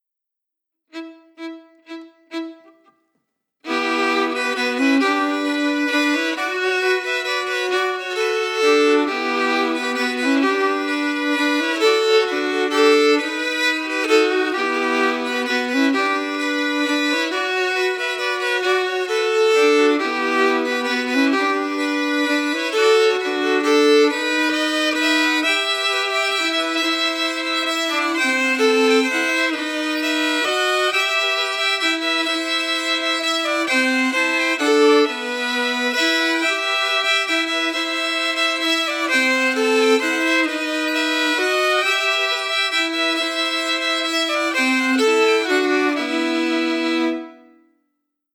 Key: Em
Form: Bourrée a  trois temps (in 3/8)
Harmony emphasis